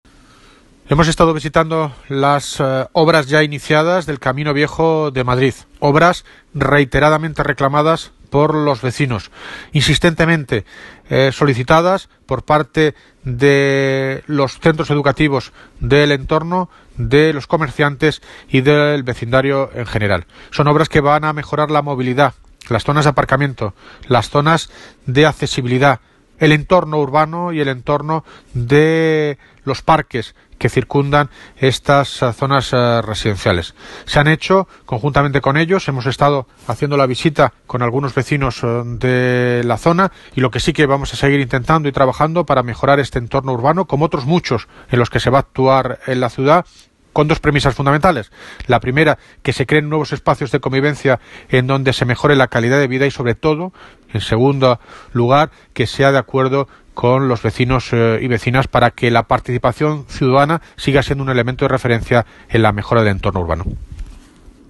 Audio - David Lucas (Alcalde de Móstoles) Sobre INICIO OBRAS CAMINO